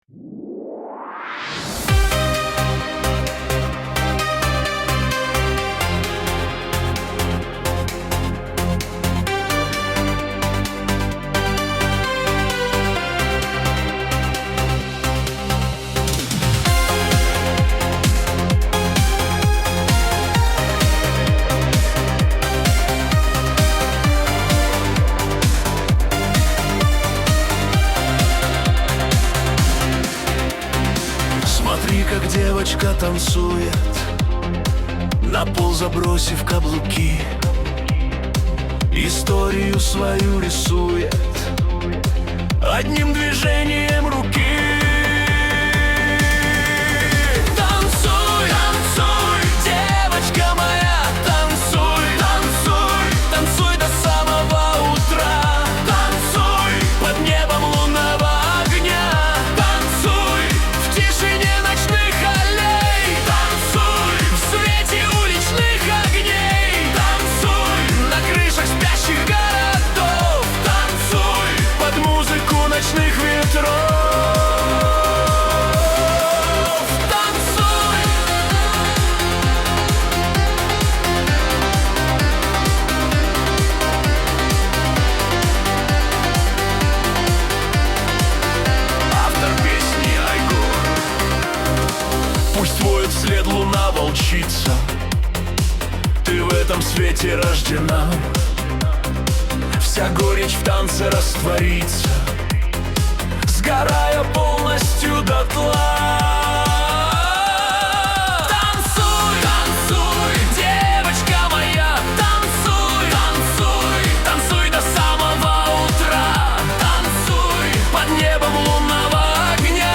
Качество: 241 kbps, stereo
Стихи, Нейросеть Песни 2025